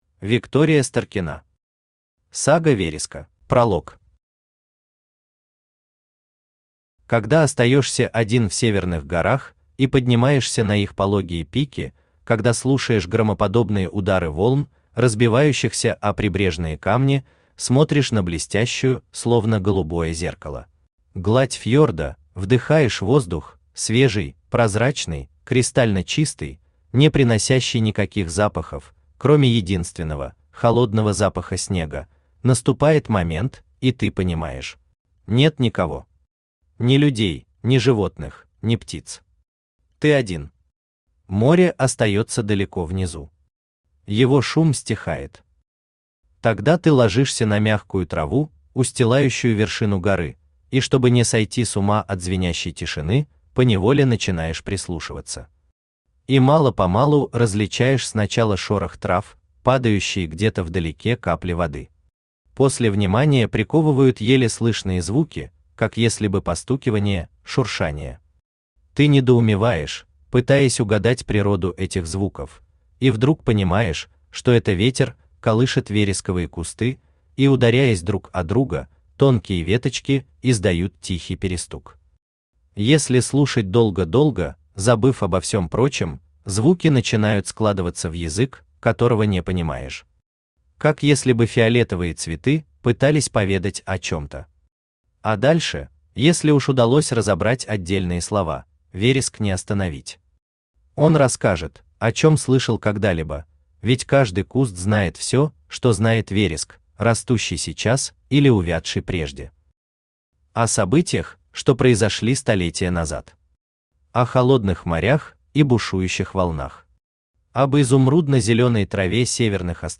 Aудиокнига Сага вереска Автор Виктория Старкина Читает аудиокнигу Авточтец ЛитРес.